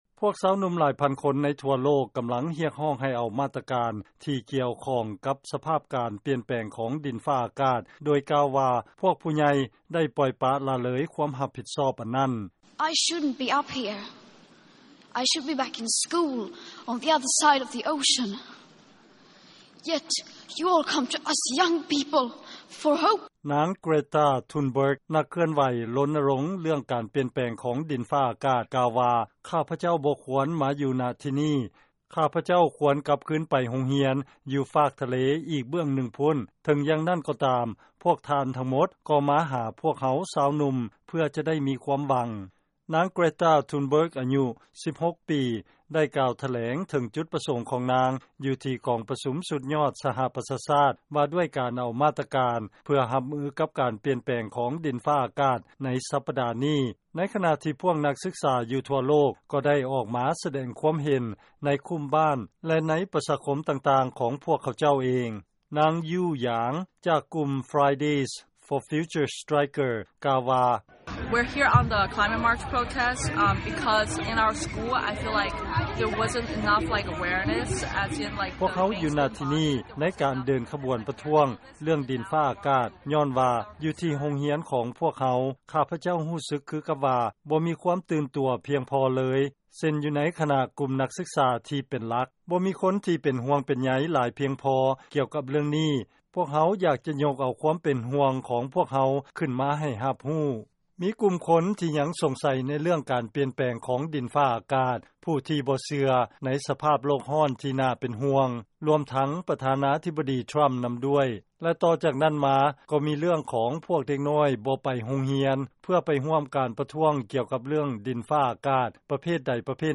ເຊີນຟັງລາຍງານ ການລົນນະລົງ ໃນເລື້ອງການປ່ຽນແປງ ຂອງດິນຟ້າອາກາດ ດຳເນີນຕໍ່ໄປ ເຖິງແມ່ນ ມີການຕຳໜິຕິຕຽນ